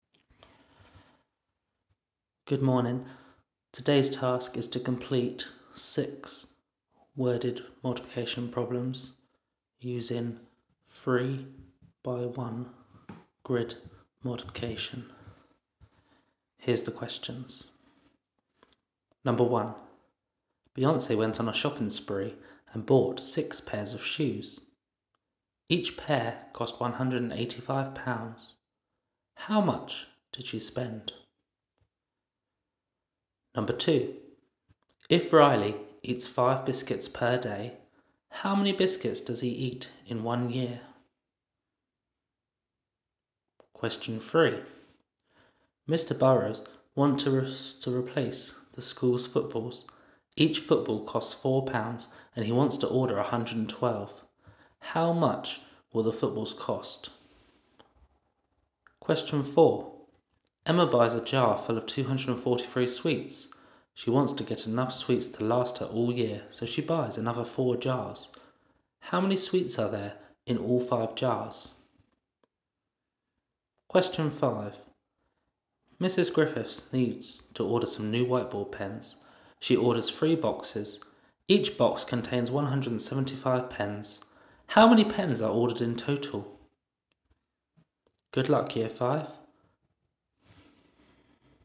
Here are some audio clips of the questions being read out loud.